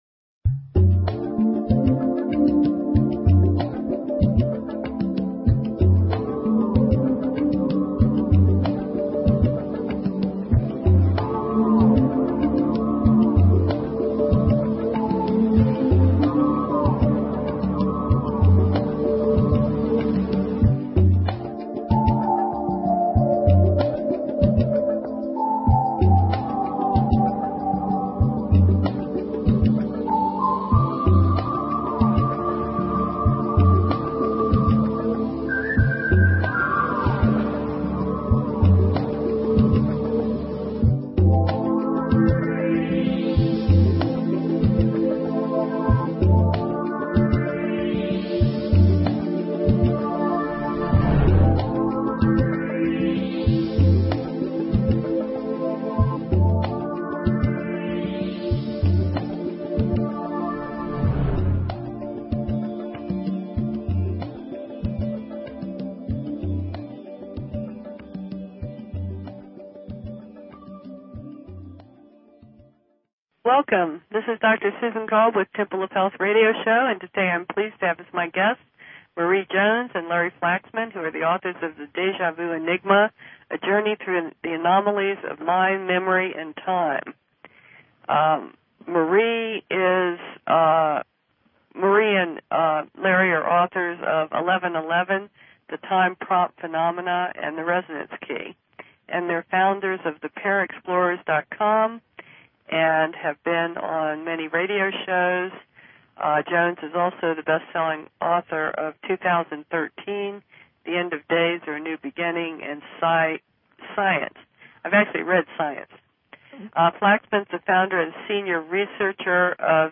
Talk Show Episode, Audio Podcast, Temple_of_Health_Radio_Show and Courtesy of BBS Radio on , show guests , about , categorized as